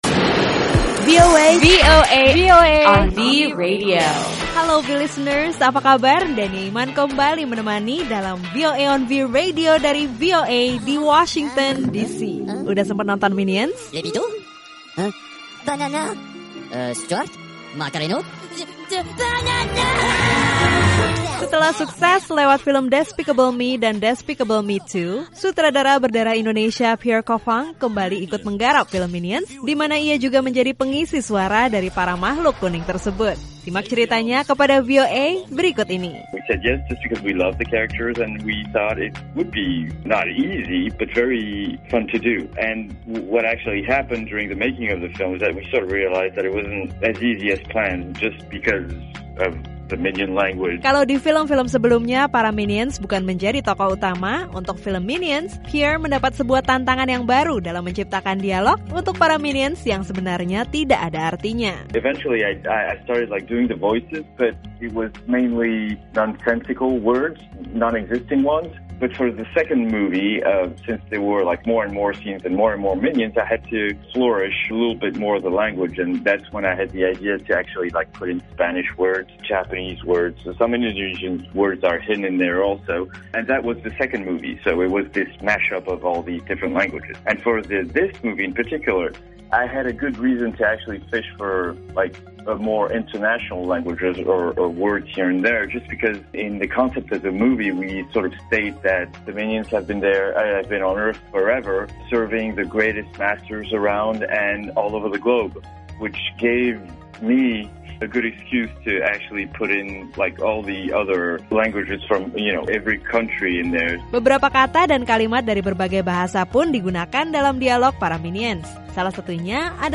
Kali ini berisi obrolan VOA Indonesia bersama sutradara film Minions yang berdarah Indonesia, Pierre Coffin, mengenai film Minions, tantangan yang ia hadapi selama penggarapan, bahasa Indonesia yang digunakan, dan komentarnya mengenai isu bahwa film ini mengandung ajaran sesat.